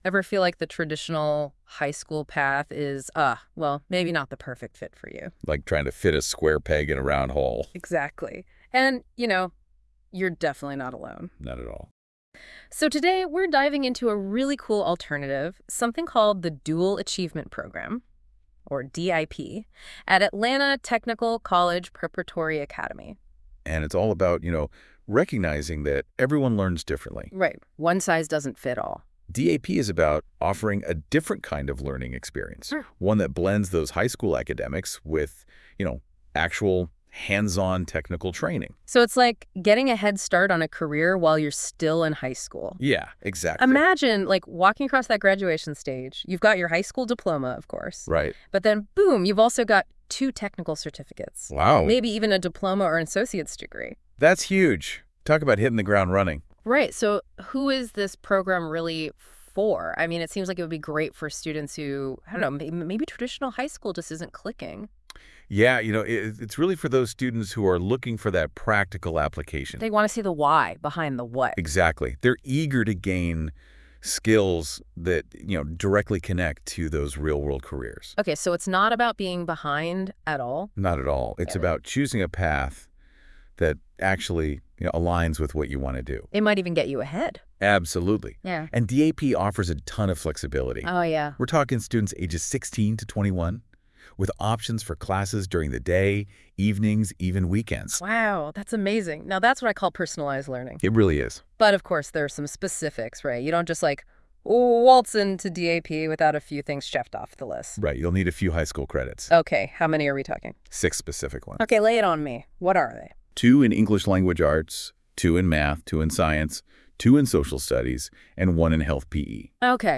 Listen to this AI-generated deep dive podcast for more information on the Dual Achievement Program.